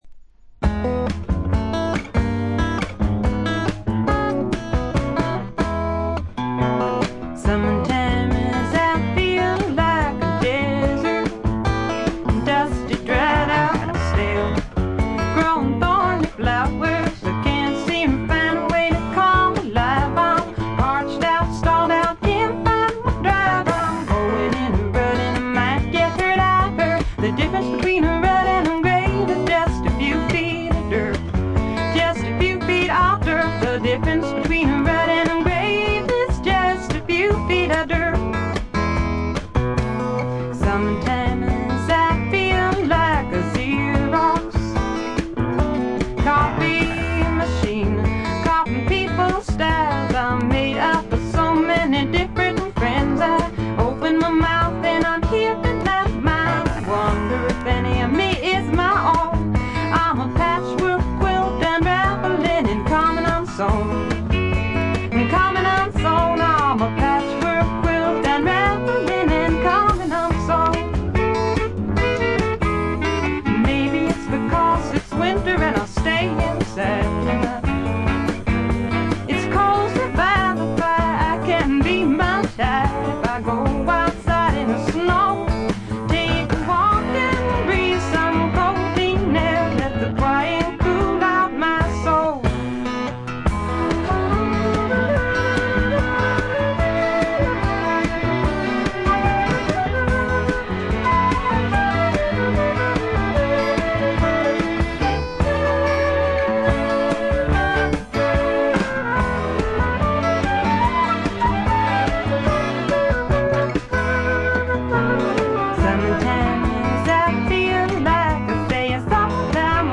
女性シンガー・ソングライター、フィメール・フォーク好きには必聴／必携かと思います。
試聴曲は現品からの取り込み音源です。
Recorded at Bearsville Sound Studios, Woodstock, N.Y.
Vocals, Acoustic Guitar